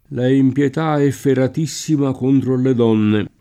la empLet# efferat&SSima k1ntro alle d0nne] (Guicciardini) — non efferrato